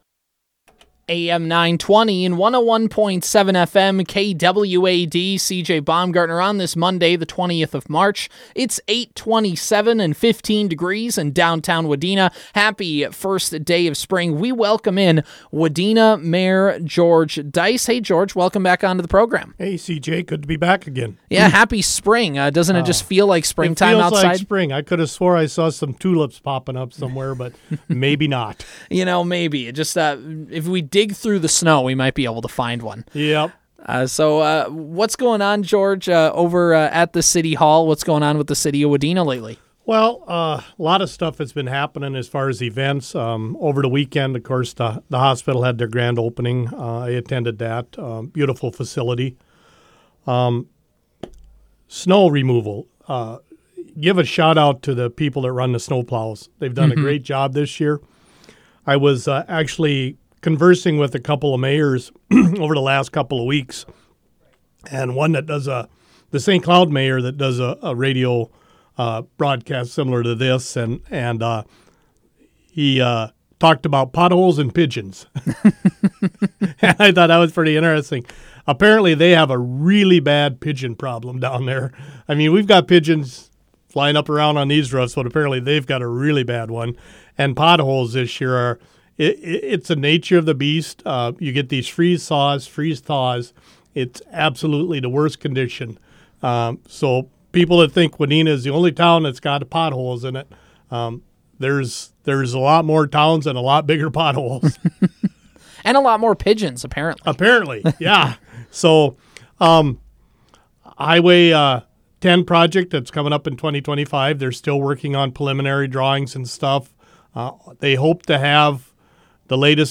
Wadena Mayor George Deiss stopped in studio to give an update on the City of Wadena and some other city announcements.
You can hear our conversation with Mayor Deiss below!